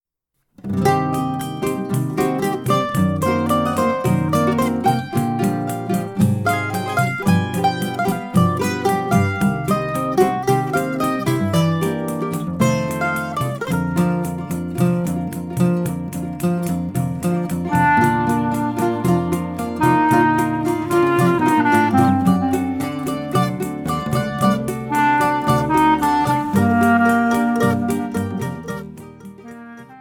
clarinet
Choro ensemble in the other songs